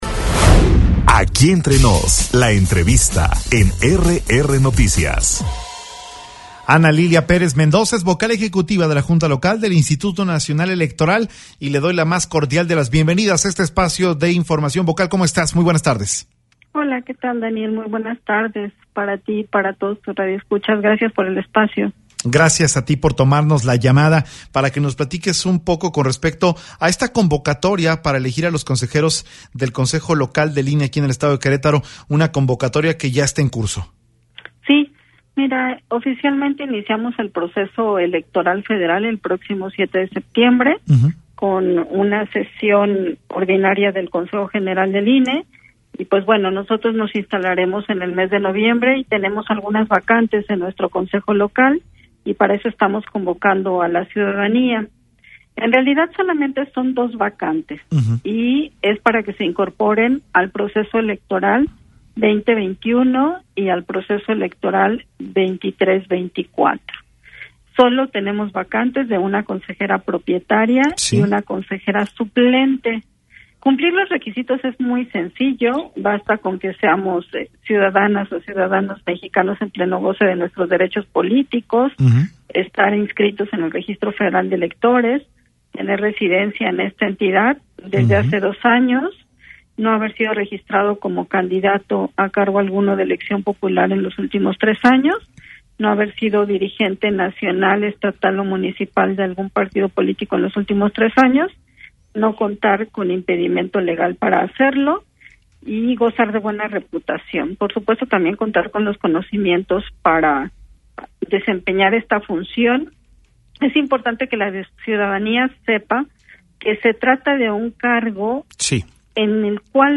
EntrevistasMultimediaPodcast